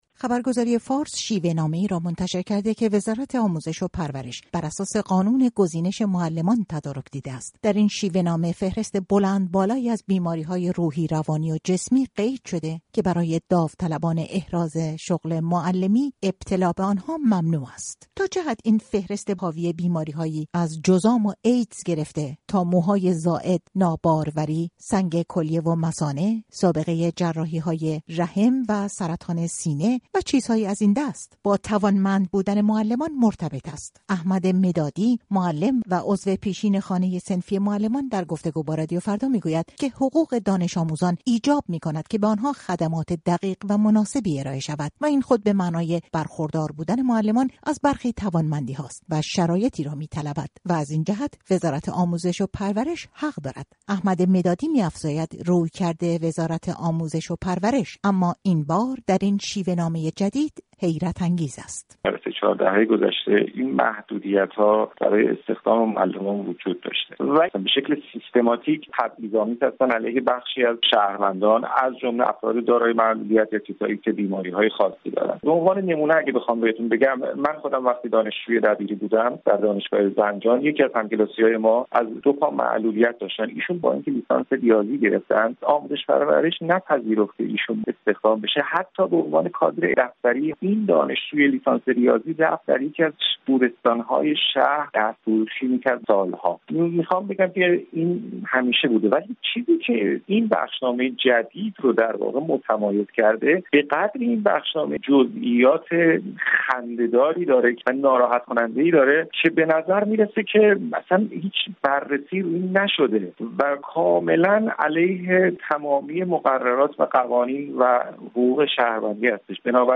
گزارش رادیو فردا را در این مورد بشنوید: